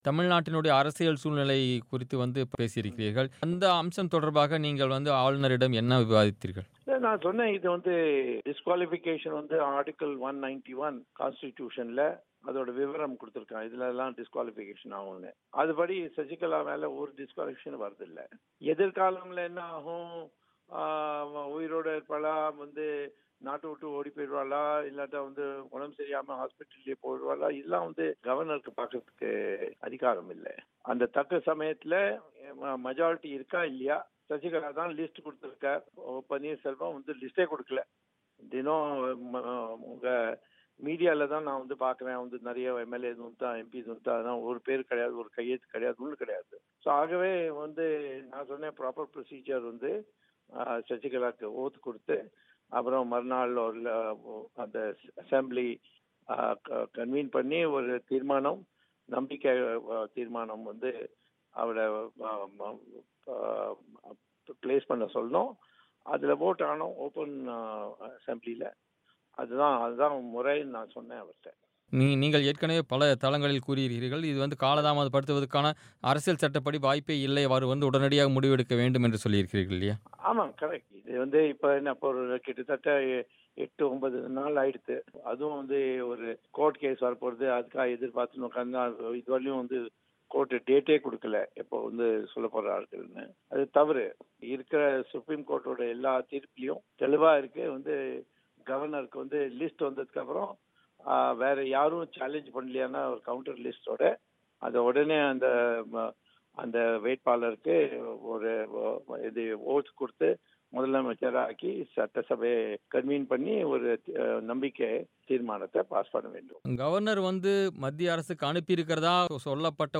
தமிழக அரசியல் குழப்பத்தில் பாரதீய ஜனதா கட்சியைச் சேர்ந்த இரண்டு மத்திய அமைச்சர்கள் ஈடுபாடு காட்டியிருக்கலாம் என்று அந்தக் கட்சியைச் சேர்ந்த மூத்த தலைவர்களில் ஒருவரான சுப்ரமணியன் சுவாமி பிபிசி தமிழுக்கு அளித்த பேட்டியில் தெரிவித்துள்ளார்.